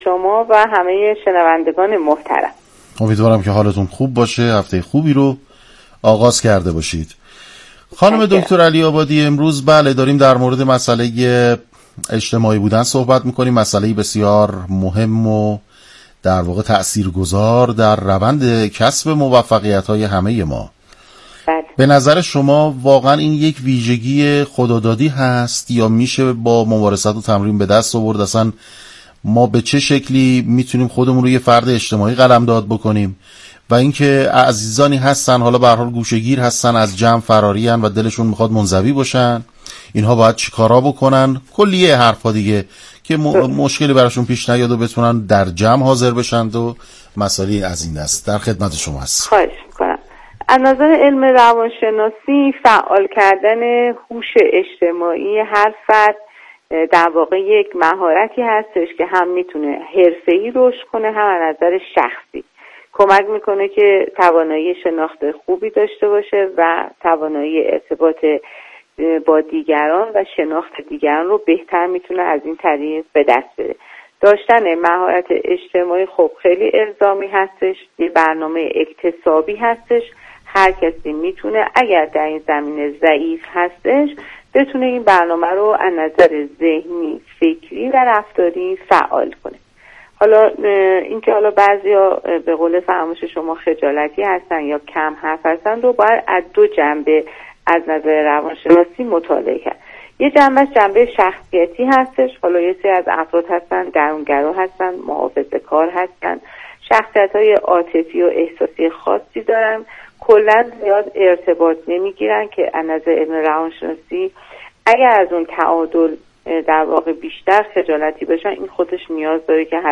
/گفتگوی رادیویی/